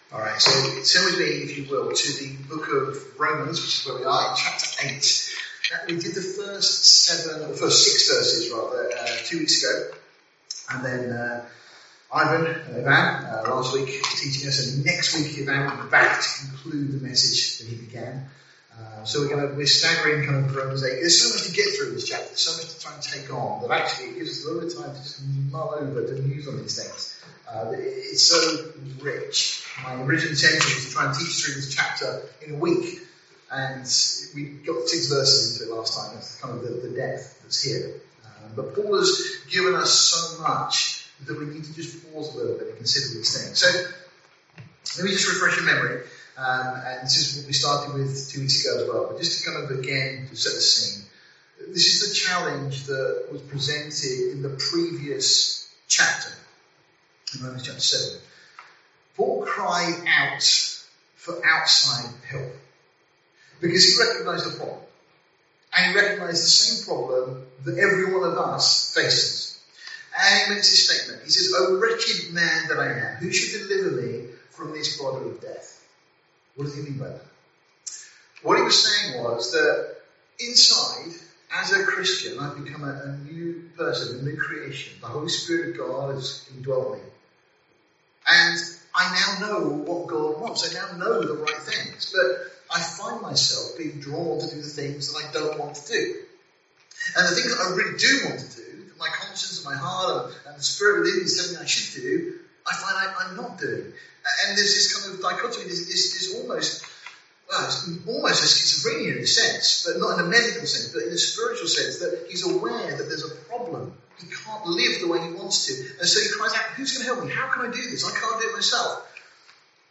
***Apologies… There was a problem with the audio quality of this recording***